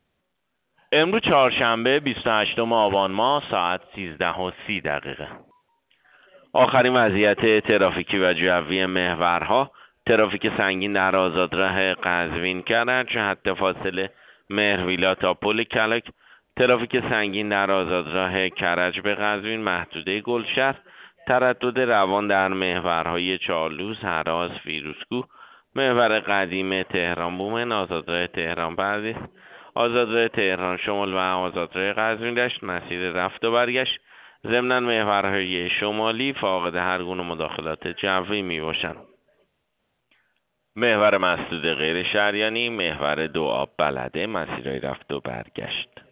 گزارش رادیو اینترنتی از آخرین وضعیت ترافیکی جاده‌ها ساعت ۱۳ بیست و هشتم آبان؛